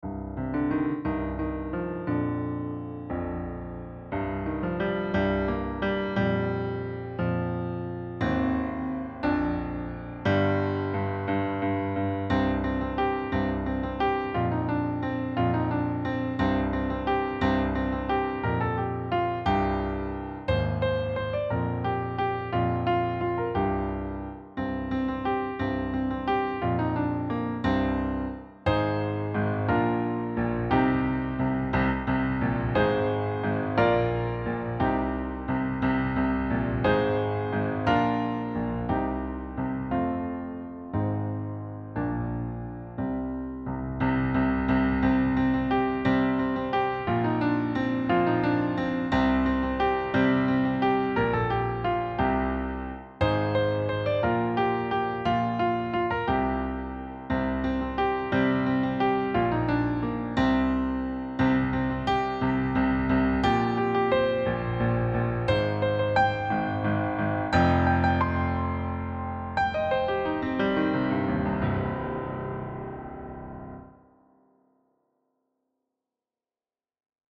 piano solo
Key: C natural minor
Time Signature: 3/4, BPM ~176